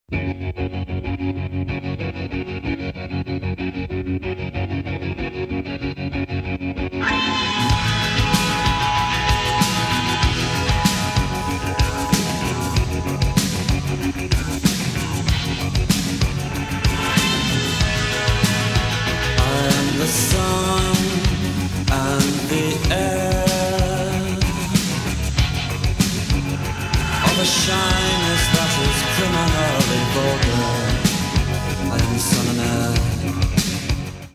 it’s probably immediately obvious how thin the low end is.